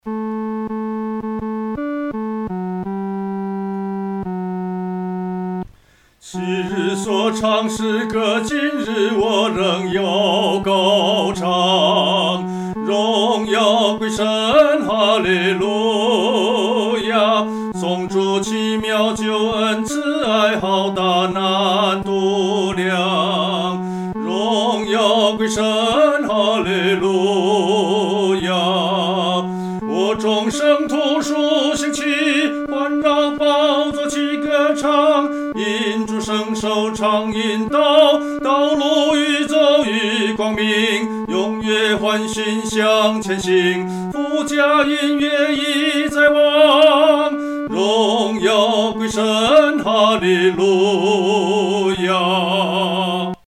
独唱（第三声）
这首赞美诗的曲调欢快，有进行曲的风味。
荣耀归神-独唱（第三声）.mp3